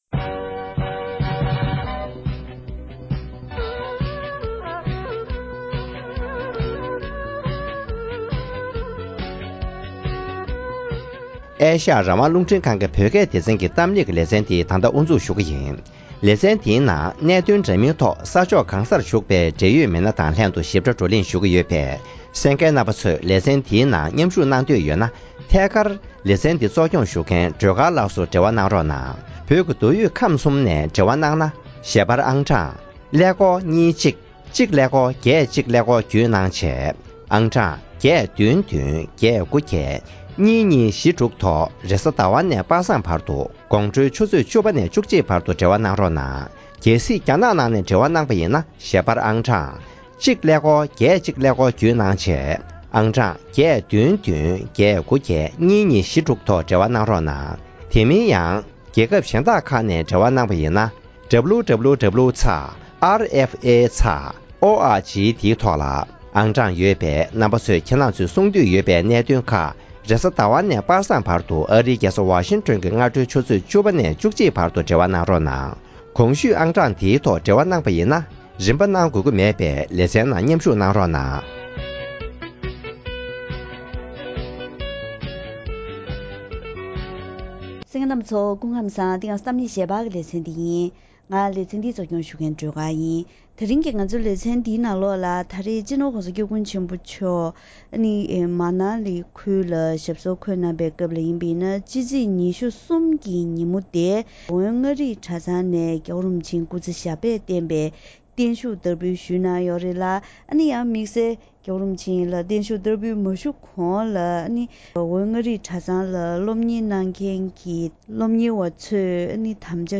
རྒྱ་གར་གྱི་མཱ་ནཱ་ལི་ཁུལ་དུ་ཡོད་པའི་འོན་མངའ་རིས་གྲ་ཚང་ནས་སྤྱི་ནོར་༧གོང་ས་༧སྐྱབས་མགོན་ཆེན་པོ་མཆོག་ལ་བརྟན་བཞུགས་བསྟར་འབུལ་སྐབས་བསྩལ་བའི་བཀའ་སློབ།